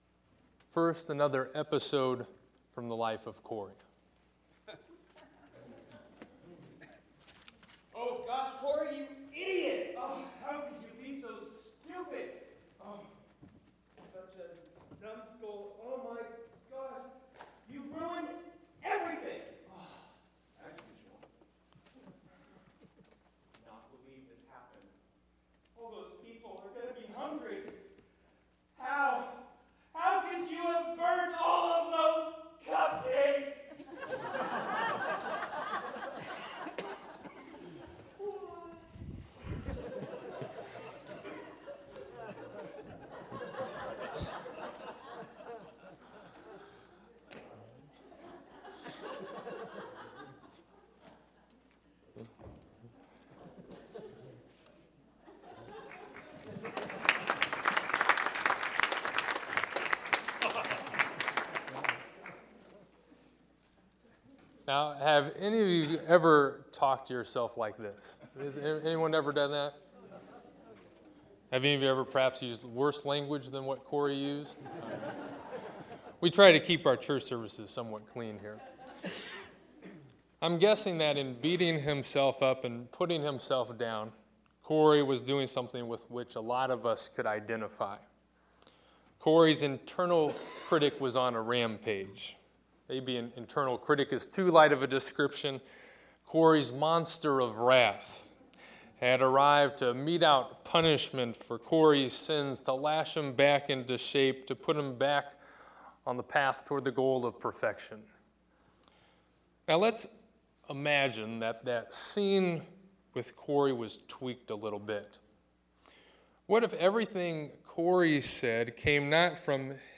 To listen to this sermon on the values of self-compassion, love of others, and gratitude to God, click here.